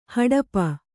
♪ haḍapa